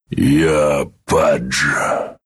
Worms speechbanks
Hello.wav